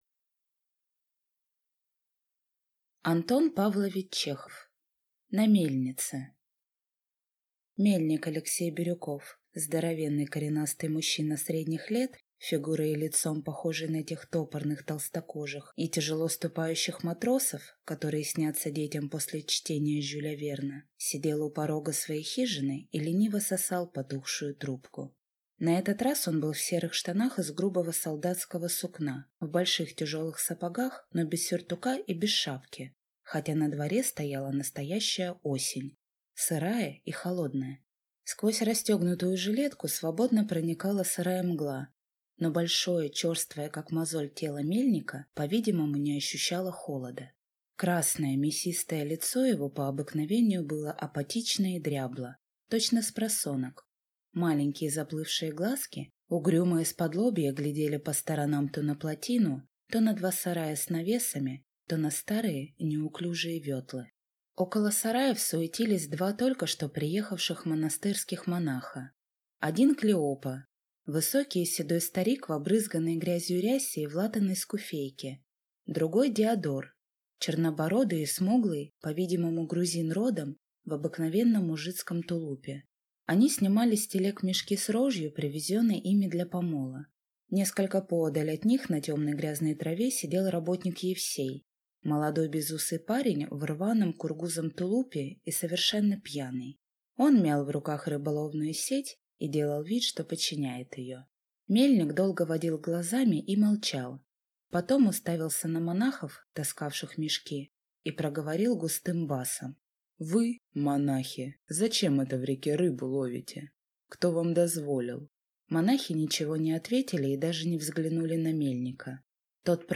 Аудиокнига На мельнице | Библиотека аудиокниг